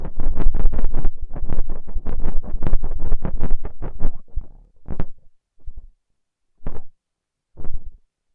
描述：a combination of freesounds 171556 amp; 171574 , flash charging whine.